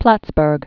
(plătsbûrg)